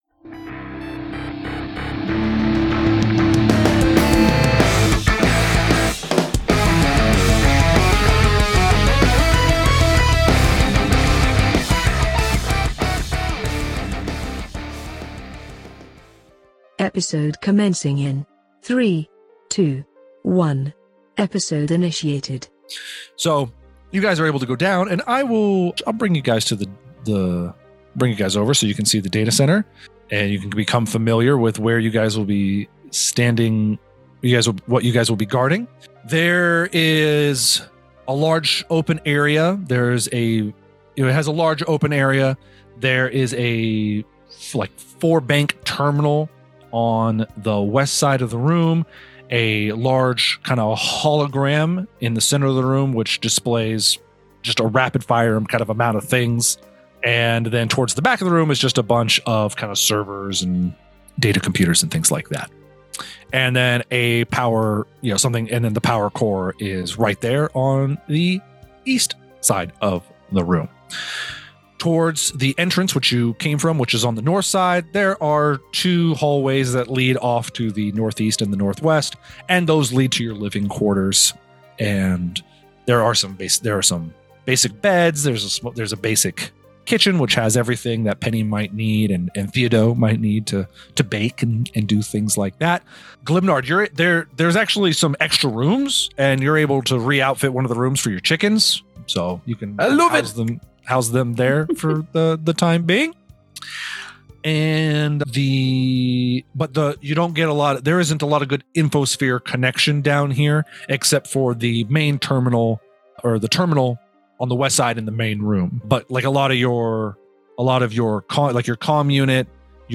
Cosmic Crit is a weekly Actual Play podcast centered on the new Starfinder RPG from Paizo. Listen to the shenanigans as a seasoned GM, a couple of noobs, and some RPG veterans explore the galaxy and fight monsters on behalf of the Starfinder Society.